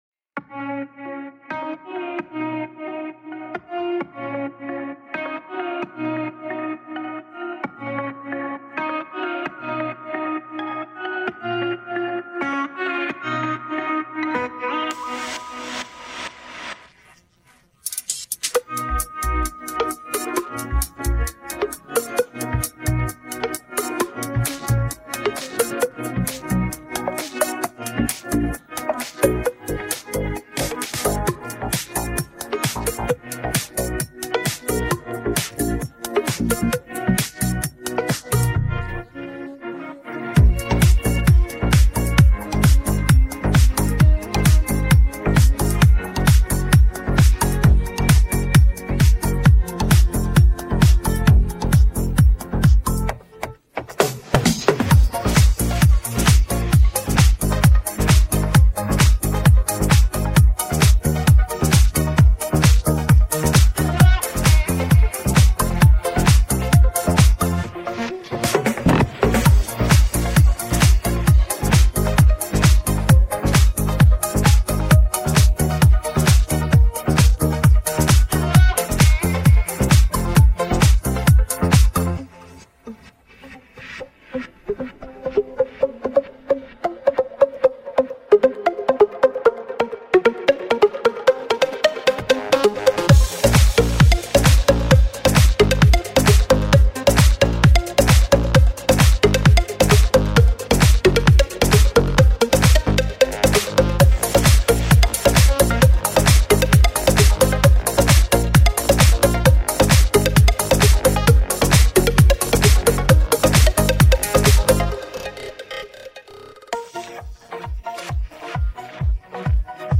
بیت بدون صدا خواننده